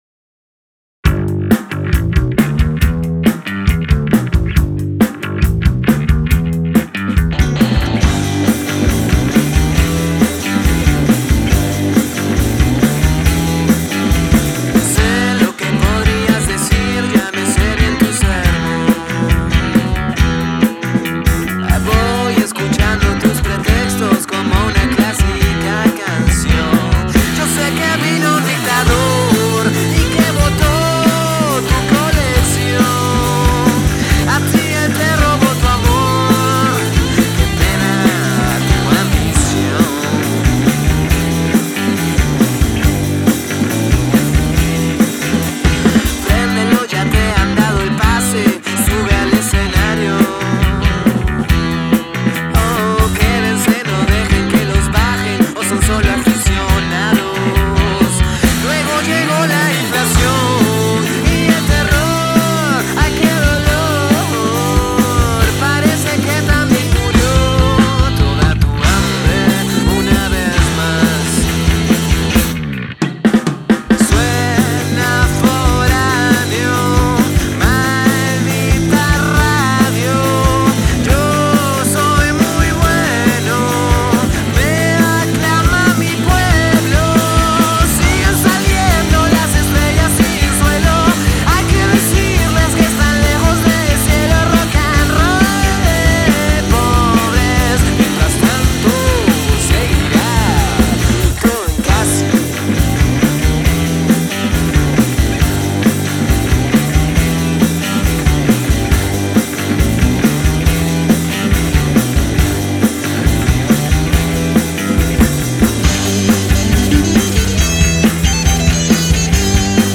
rocanrol en su estado más puro.
Rock and roll